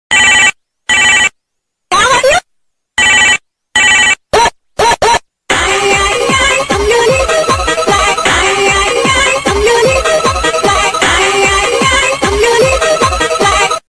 Ringtone Legend Sound Effect . sound effects free download